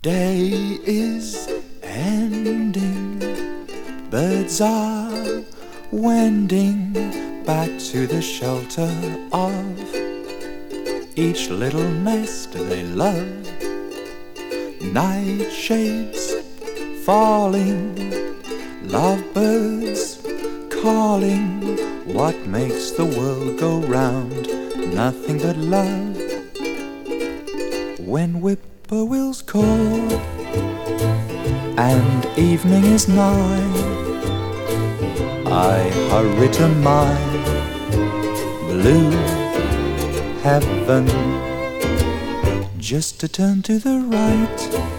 Jazz, Pop, Ragtime, Big Band　USA　12inchレコード　33rpm　Stereo